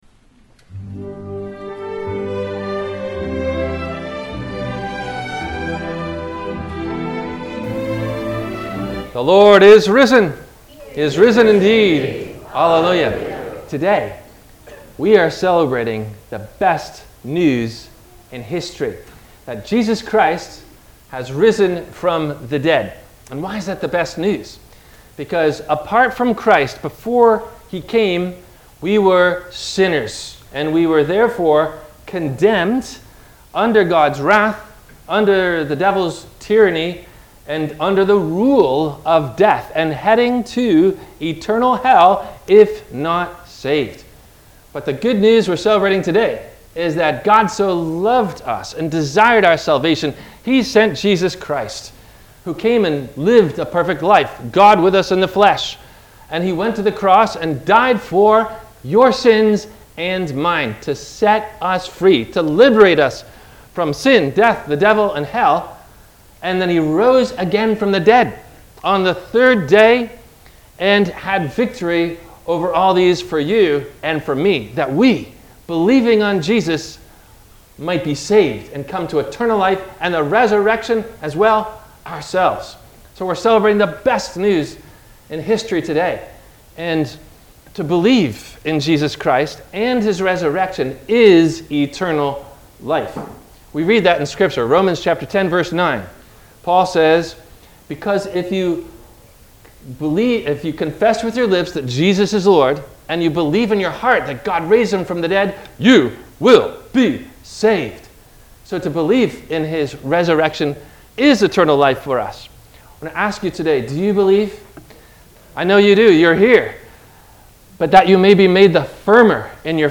Did Any Believe In The Resurrection When Jesus Was On The Cross? – WMIE Radio Sermon – April 17 2023 - Christ Lutheran Cape Canaveral
Including closing Plug only.